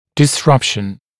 [dɪs’rʌpʃn] [дис’рапшн] нарушение, разрыв, разрушение